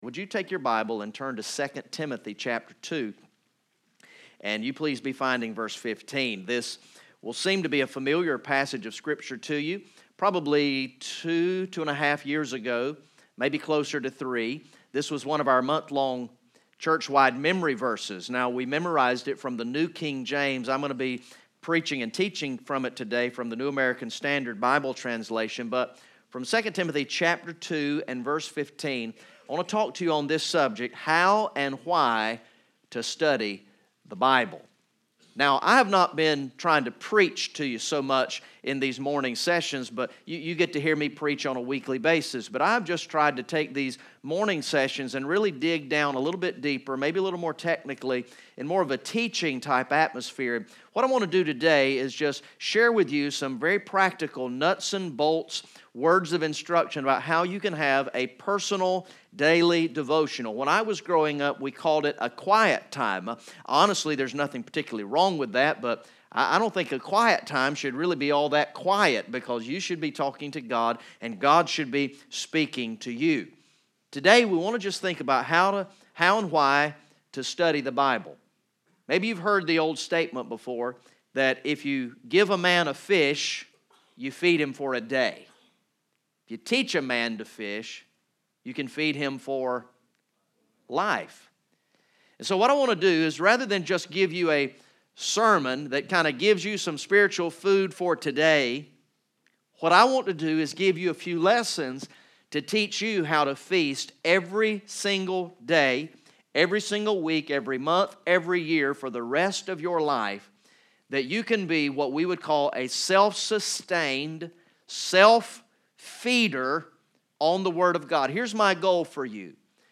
From the morning session of ESM Summer Camp on Thursday, June 28, 2018